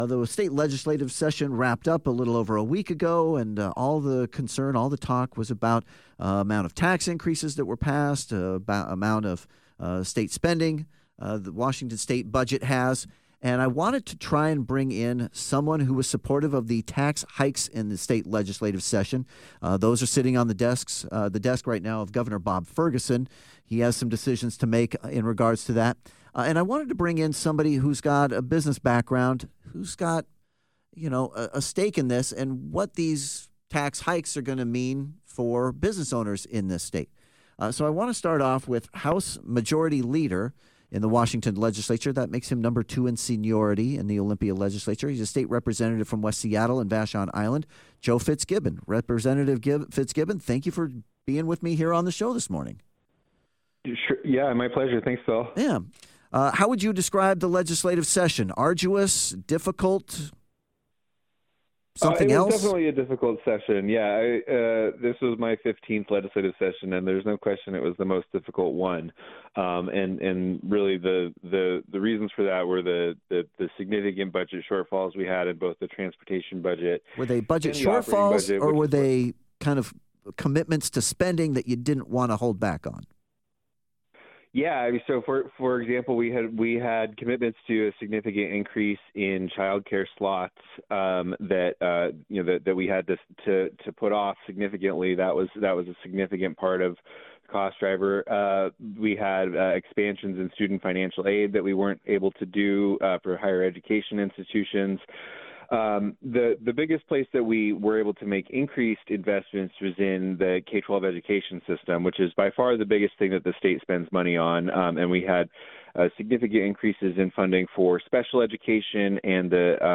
Washington House Majority Leader Representative Joe Fitzgibbon joins The Morning Ride alongside former state lawmaker and small business owner Kelly Chambers to discuss and defend the recent tax increases passed by the state legislature.